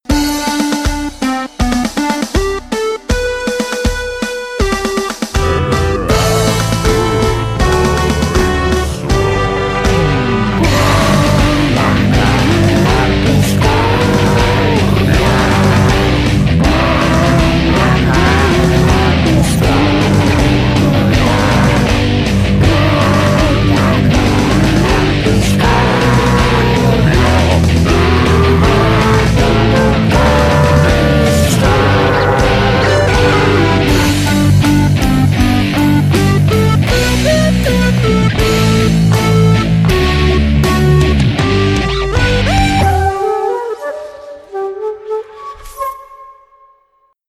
disco_short.mp3